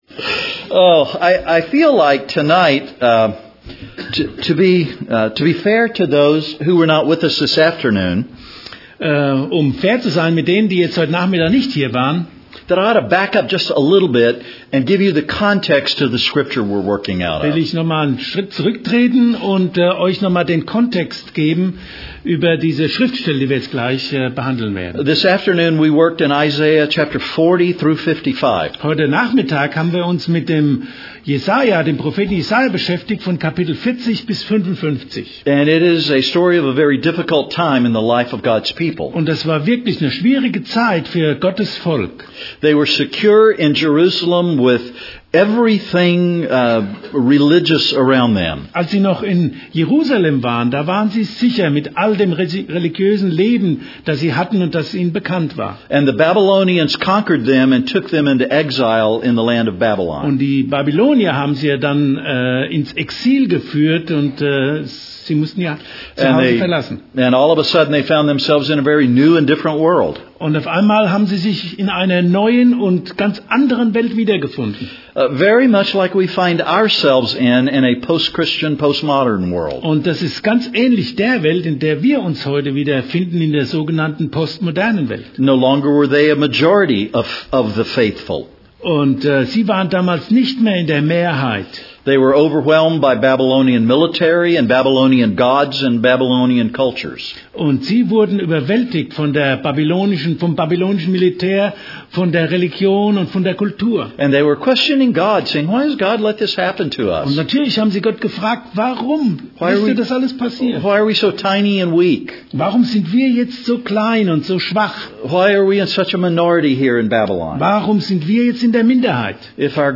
EuNC LC 2013 - Wednesday, 30 January 2013 - Evening Service
European Nazarene College Leadership Conference